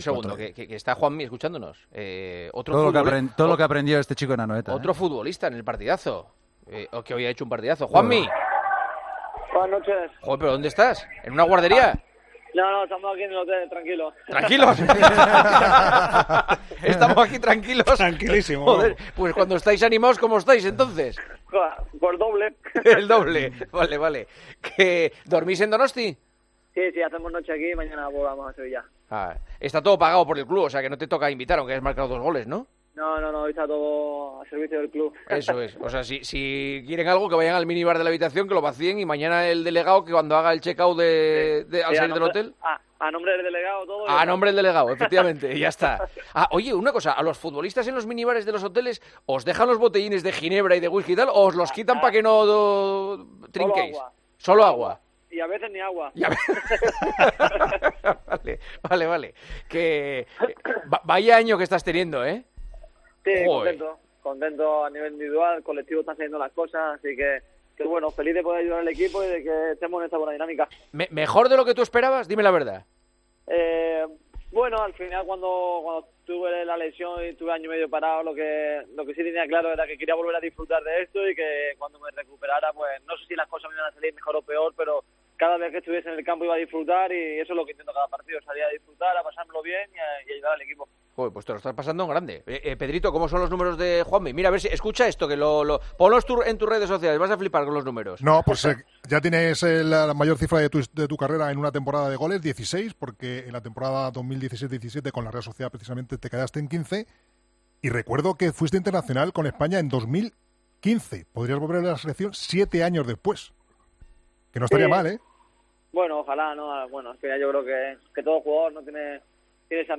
El delantero del Betis ha anotado dos goles en la victoria de su equipo ante la Real Sociedad en Anoeta y, después, ha estado con Juanma Castaño en El Partidazo de COPE.